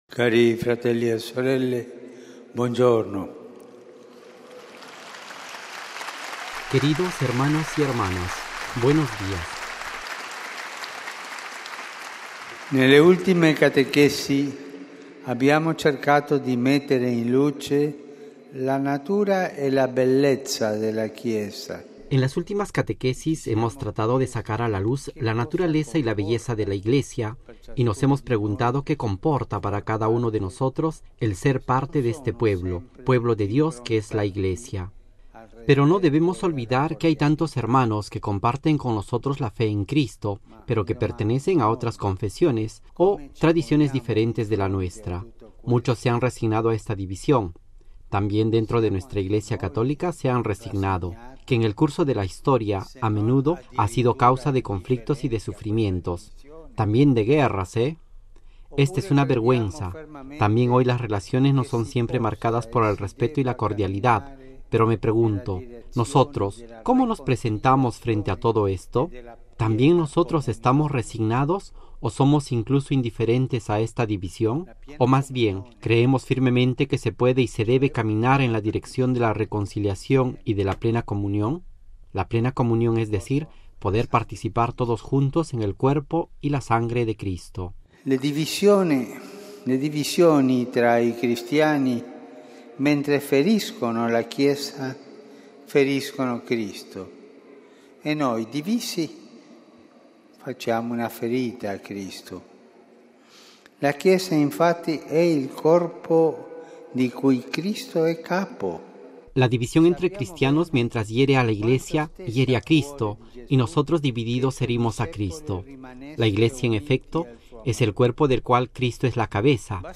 (RV).- (Actualizado con texto y audio completo de la catequesis del Papa) RealAudioMP3 ¿Cuál es nuestra actitud frente a tantas personas que, compartiendo nuestra fe en Cristo, pertenecen a otros confesiones o tradiciones?, preguntó el Obispo de Roma, en la Plaza del santuario de San Pedro, colmada de fieles y peregrinos del mundo, en la Catequesis dedicada a los cristianos no católicos.
Texto completo de la catequesis del Papa RealAudio